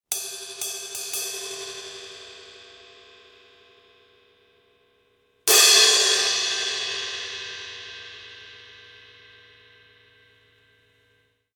- und zwei Paiste 2000 Crashes in 16“,